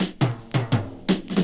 Army Fill.wav